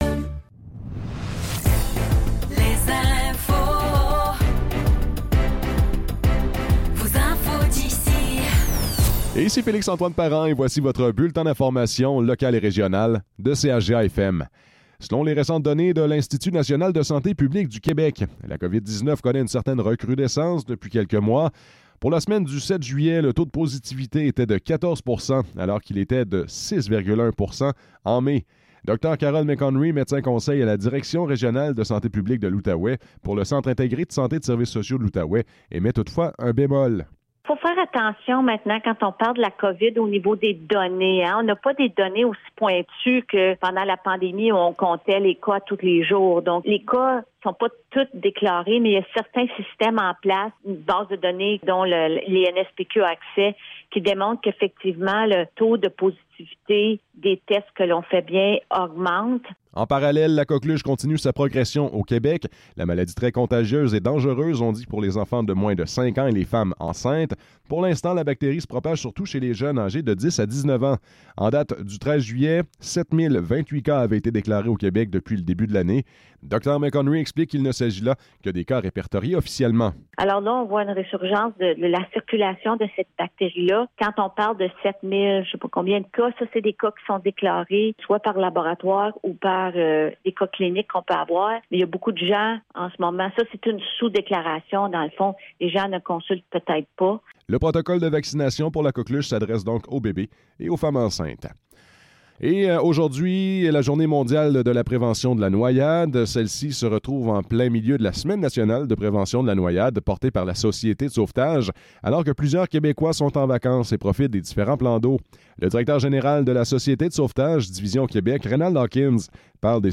Nouvelles locales - 25 juillet 2024 - 10 h